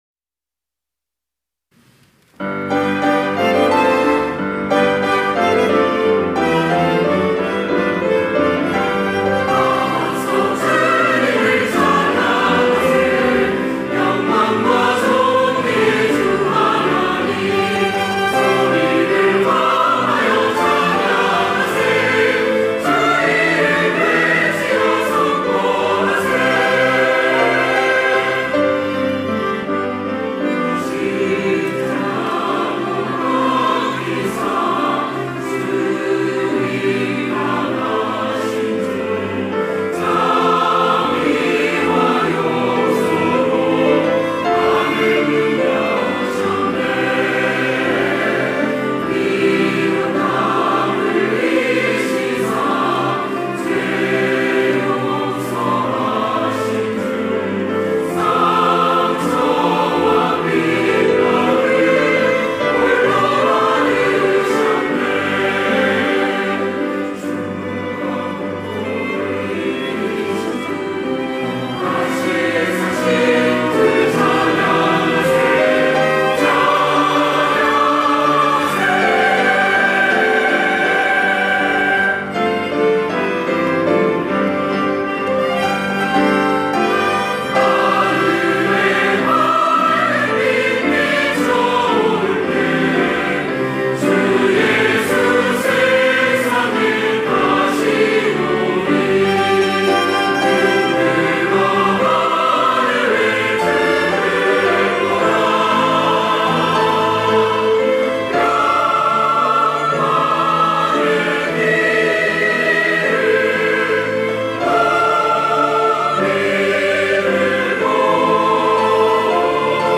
호산나(주일3부) - 다 와서 주님을 찬양하세
찬양대